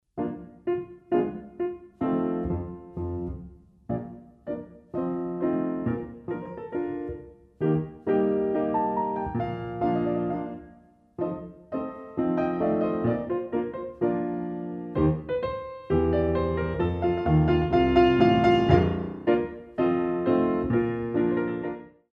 Young dancers Ballet Class
The CD is beautifully recorded on a Steinway piano.
Dégagés dans la dynamique